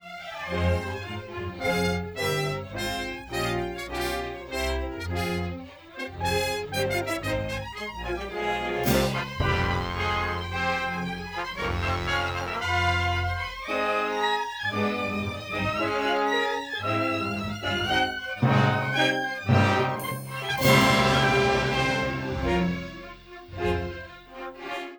Section VI - Mono Rendering of 24 channel Anechoic Orchestra
MonoAnechoic.wav